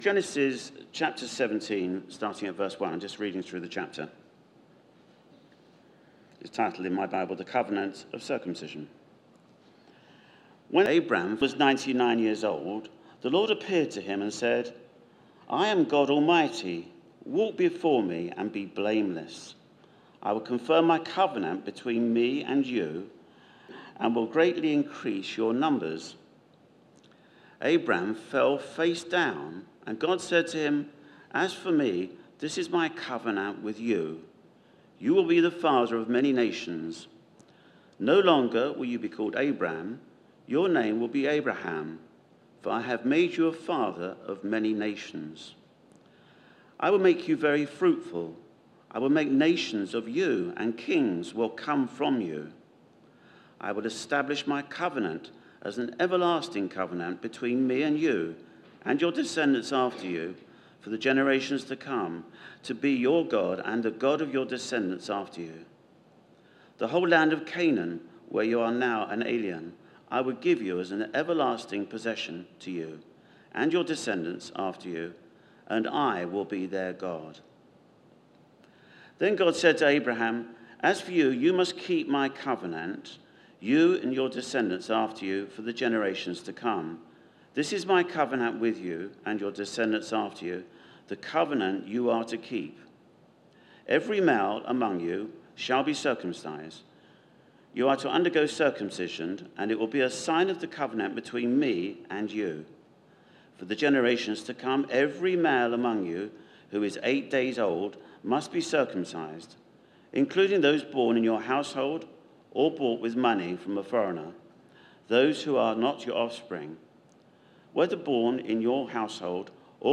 Theme: Sermon